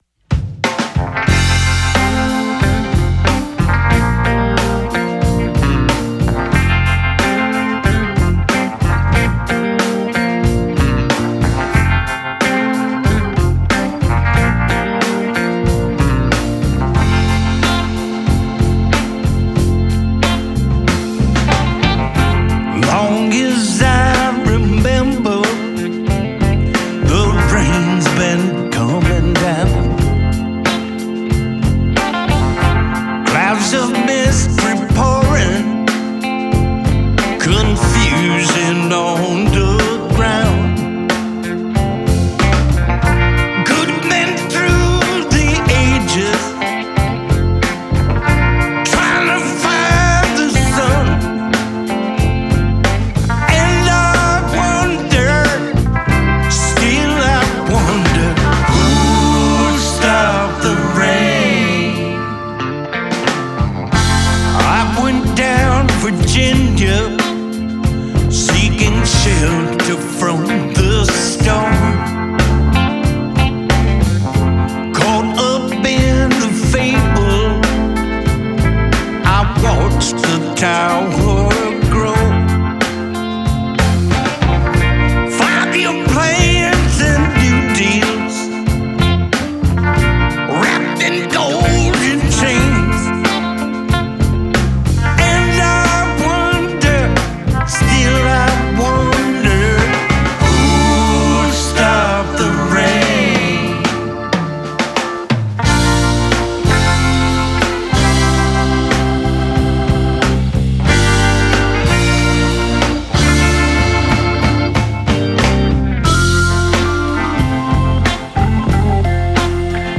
a lemez címe enyhén megtévesztő: szó sincs blues-ról.